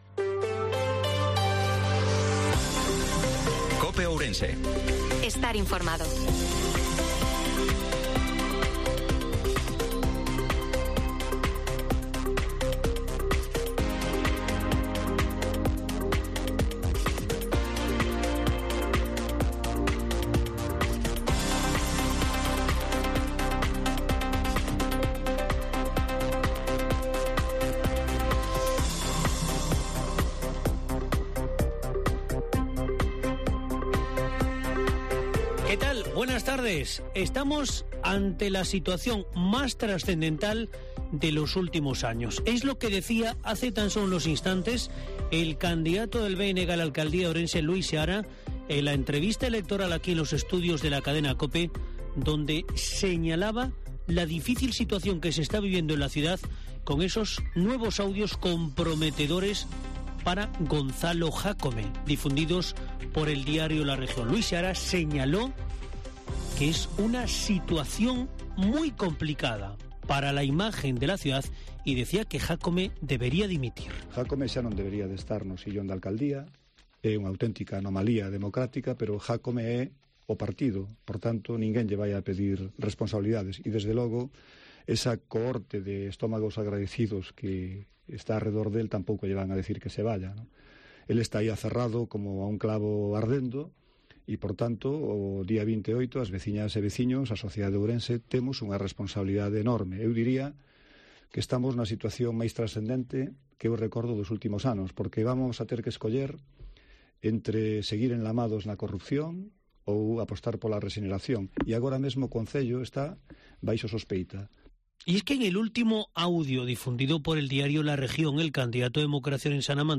INFORMATIVO MEDIODIA COPE OURENSE-22/05/2023